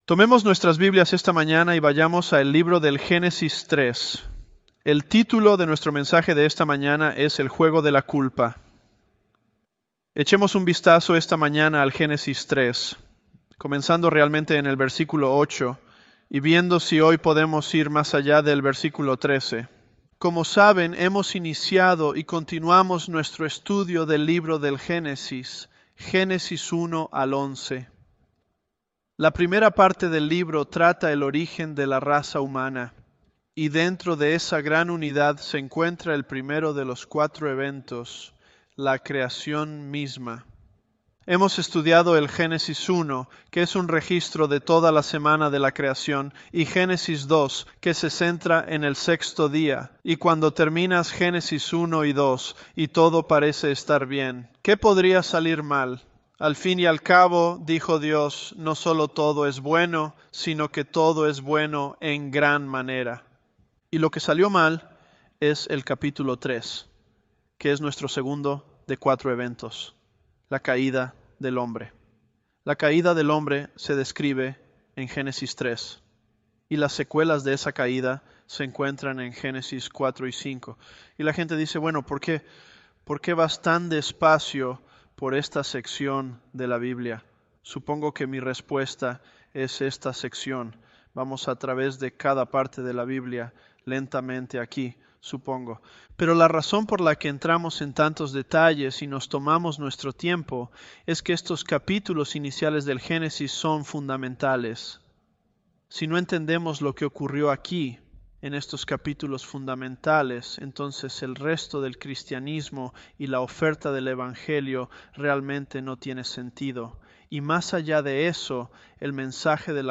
ElevenLabs_Genesis-Spanish013.mp3